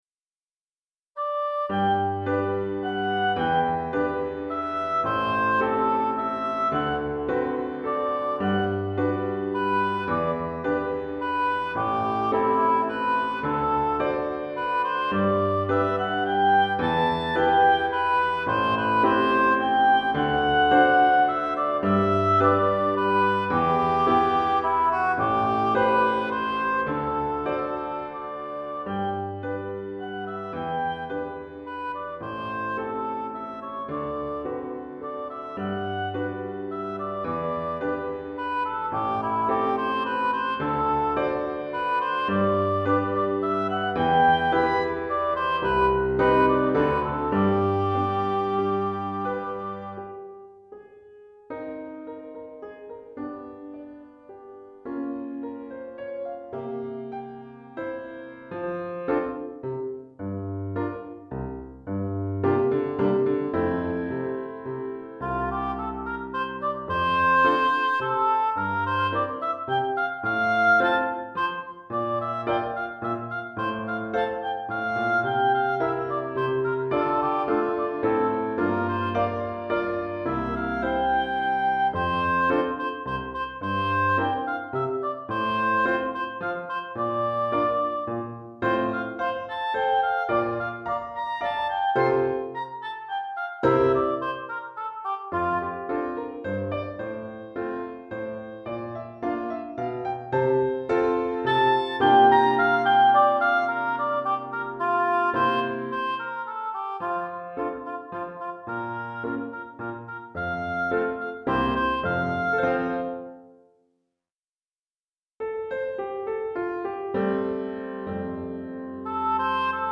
Pour hautbois et piano DEGRE CYCLE 1